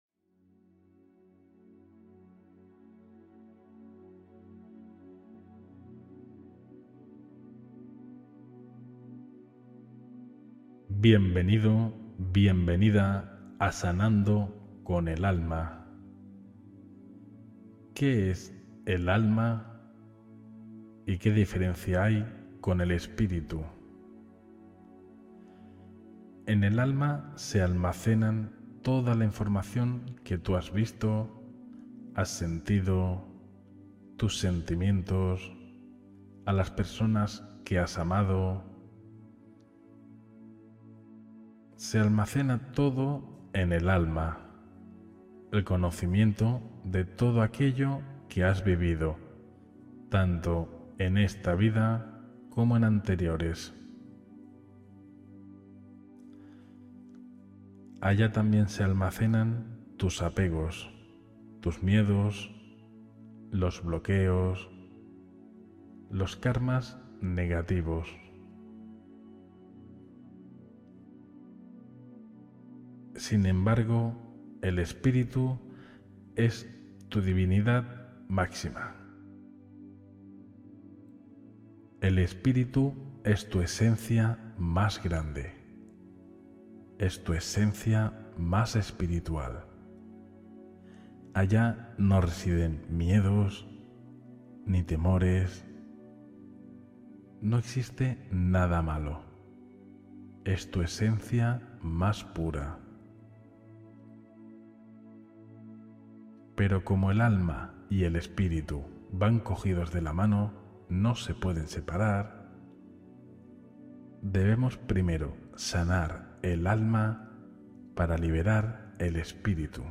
Meditación guiada para sanar el alma y el espíritu con sonido armonizador